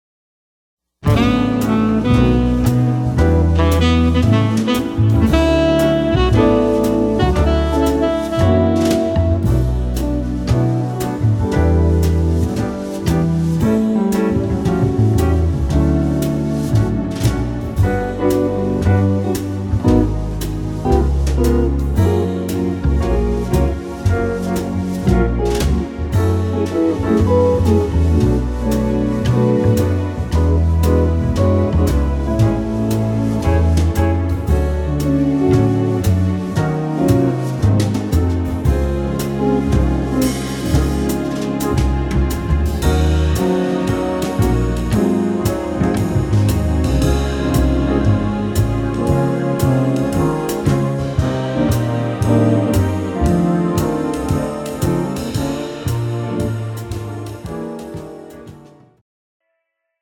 jazz ballad style
tempo 115 bpm
female backing track
This track is in medium tempo jazz ballad style.